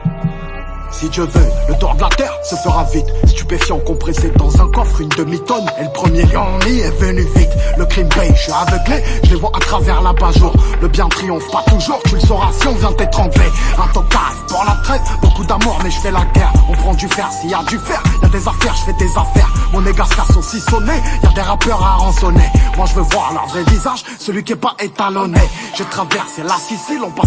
French Rapper